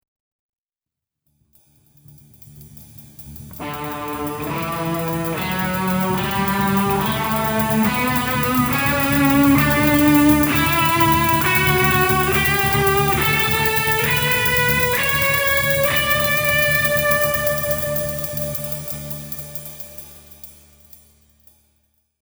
FIGURE 1a illustrates a D major scale in two octaves. First, play and sing each scale degree simultaneously.